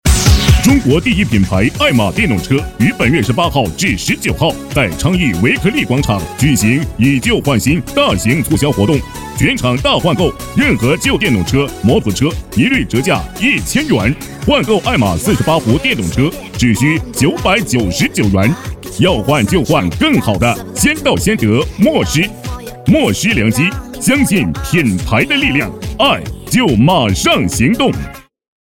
B男100号
【促销】车品促销demo
【促销】车品促销demo.mp3